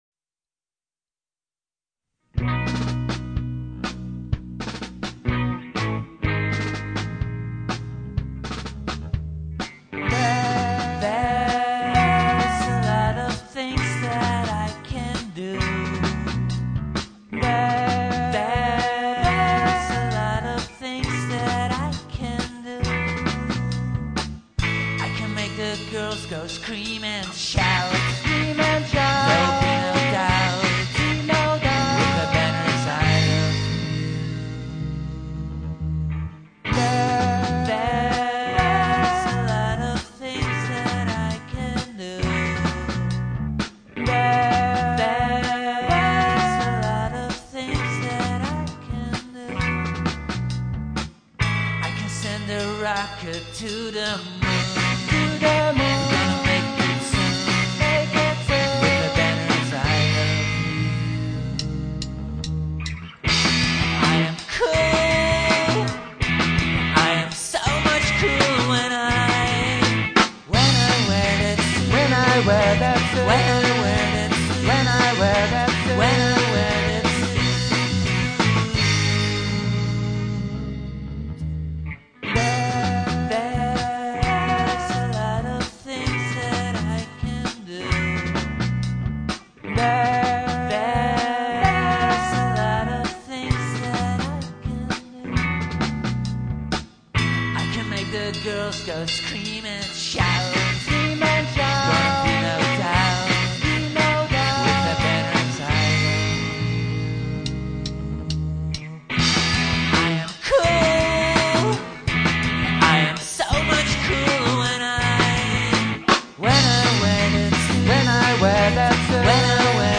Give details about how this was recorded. where: recorded at CMA (Amsterdam)